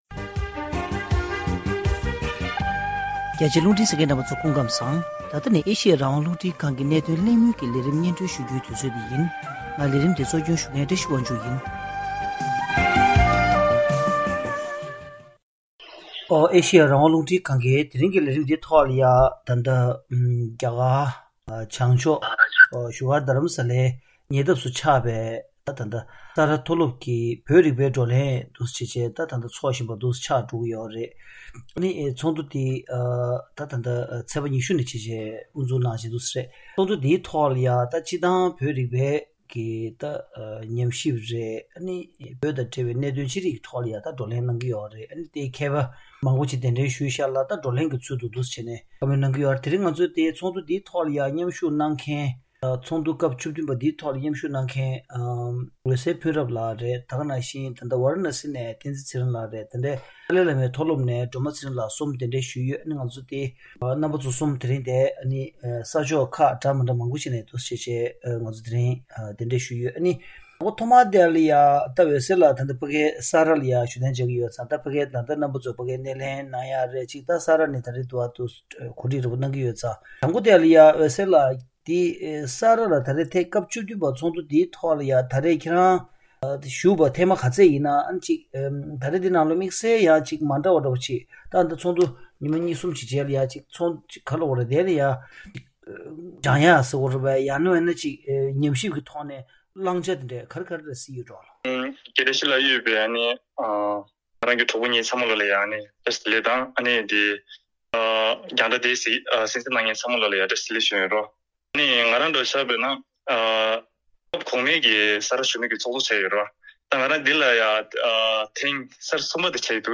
བོད་རིག་པའི་ཉམས་ཞིབ་པ་དང་ཞིབ་འཇུག་གི་བརྗོད་གཞིའི་སྐོར་གླེང་པ།